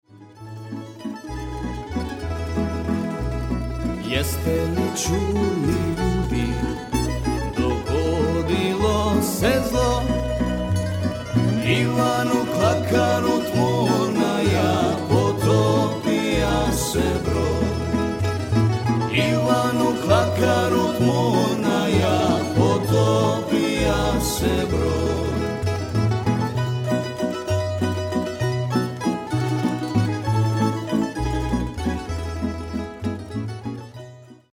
South Slavic American Musical Traditions
prim, brac and celo
bugarija and vocals